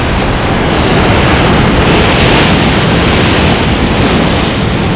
WAVES.WAV